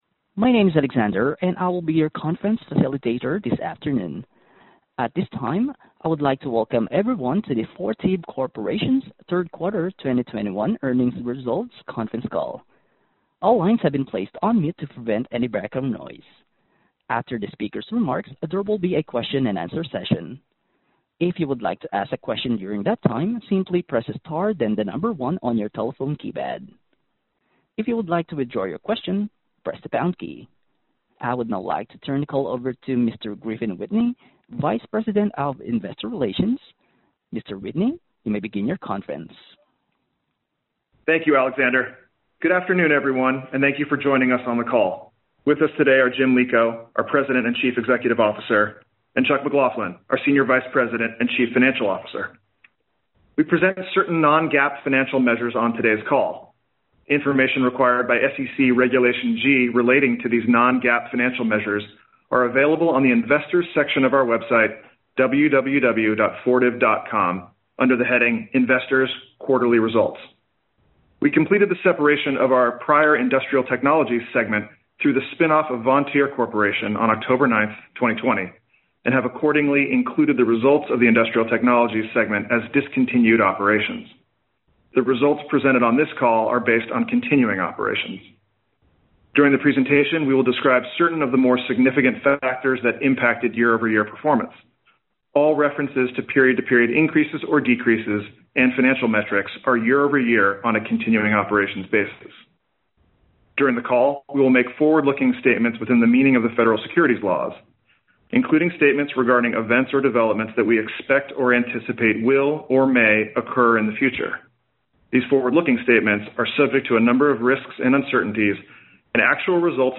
Earnings Webcast Q3 2021 Audio